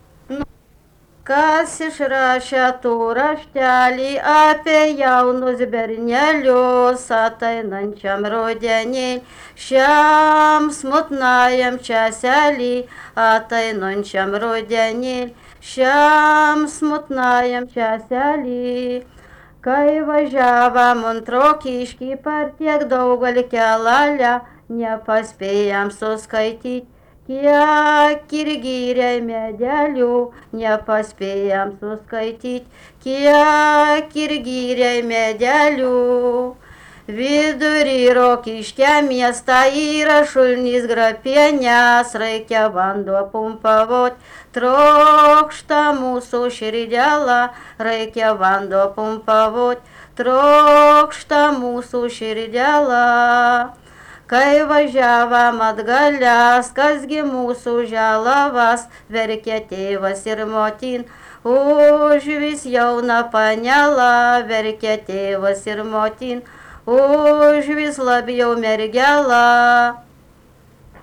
daina
Papiliai (Rokiškis)
vokalinis